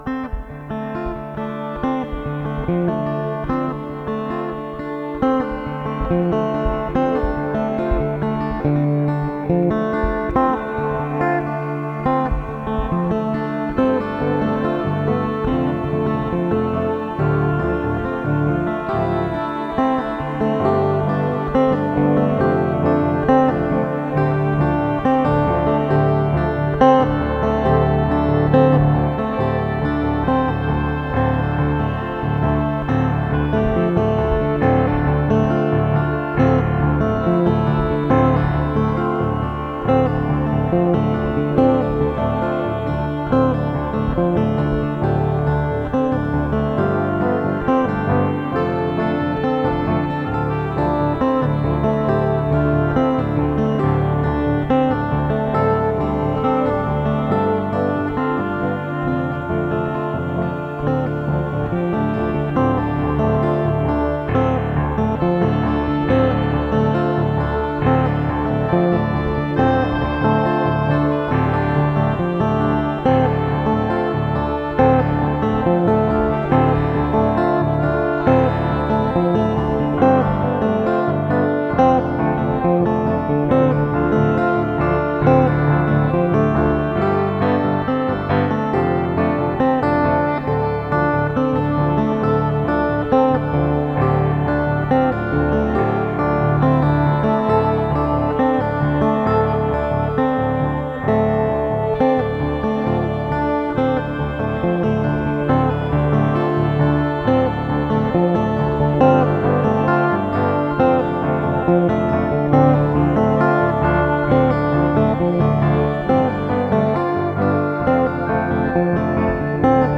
Folk Classic relaxed.